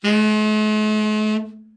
Index of /90_sSampleCDs/Giga Samples Collection/Sax/SAXOVERBLOWN
TENOR OB   3.wav